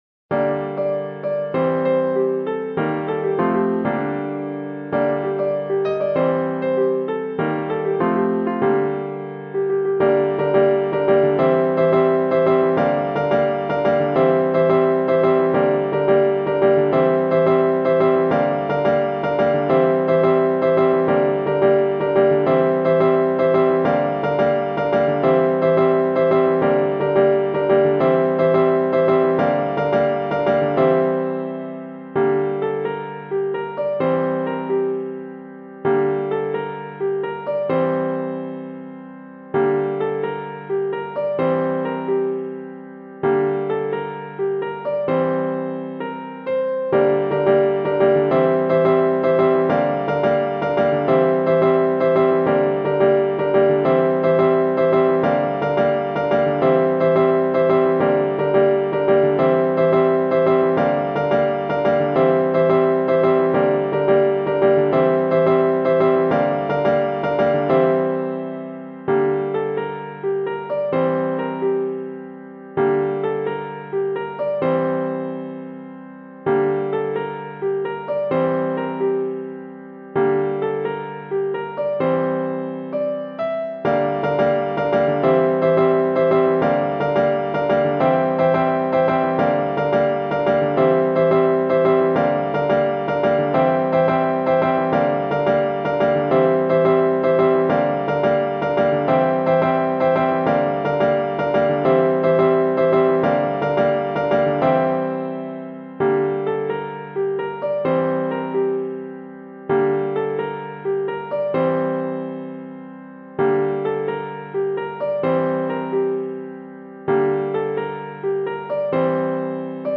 Chanson sans paroles - Fichier MIDI de la musique